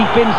final whistle went.
fulltime.wav